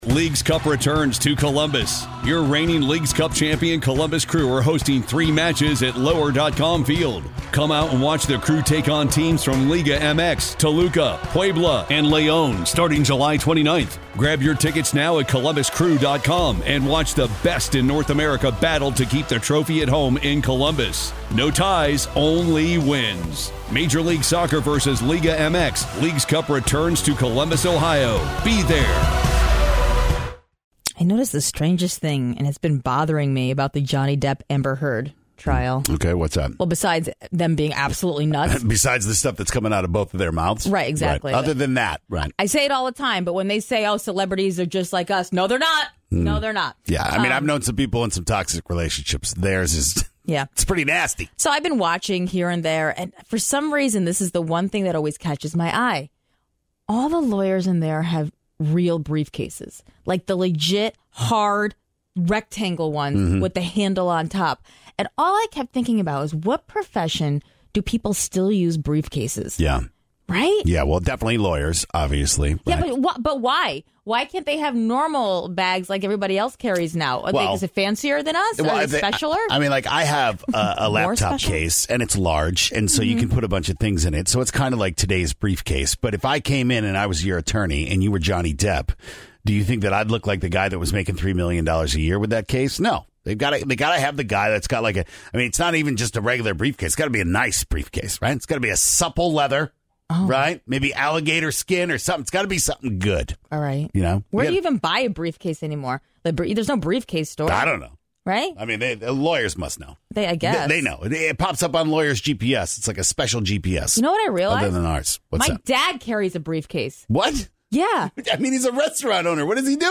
What professions usually have briefcases? We had some real questions here, and luckily, you called in and gave us some answers.